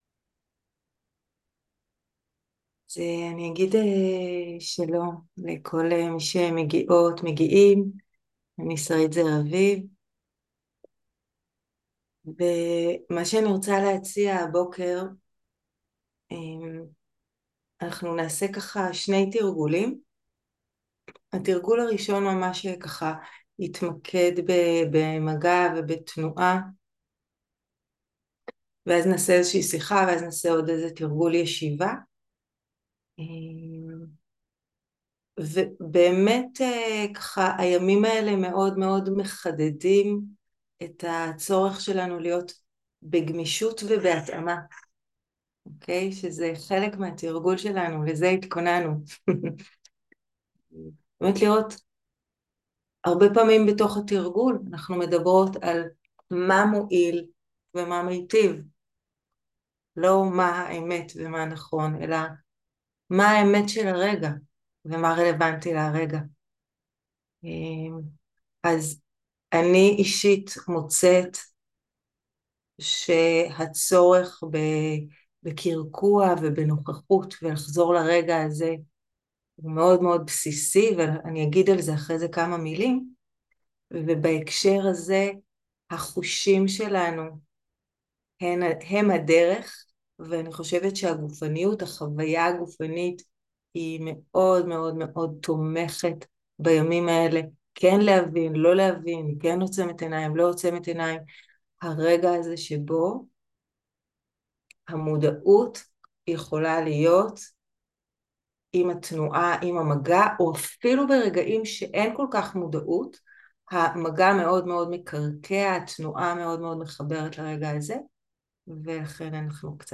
18.10.2023 - מרחב בטוח - חזרה לנוכחות, הפרדה, יזמות חשבתית, מחשבות מטיבות - שיחה, מדיטציה ותרגול בתנועה